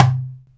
DUMBEK 2A.WAV